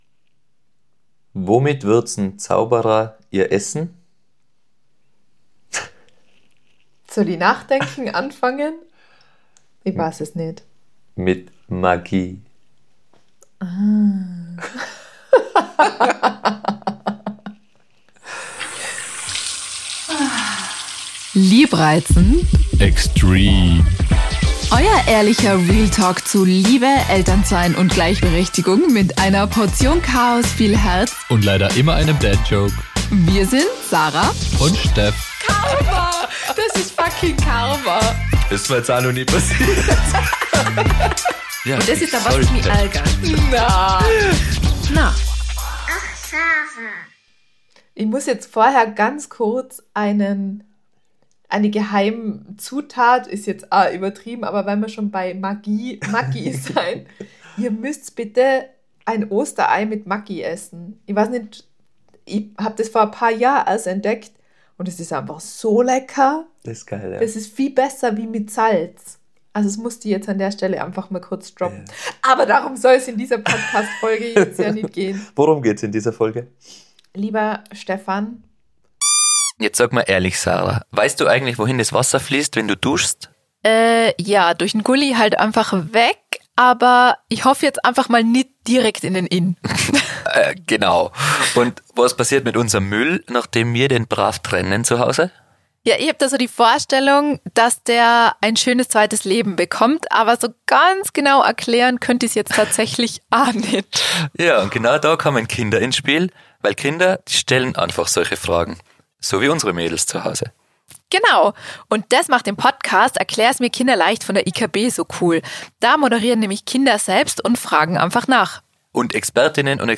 Wir sind noch völlig überwältig, wie man vielleicht unseren Stimmen anhört.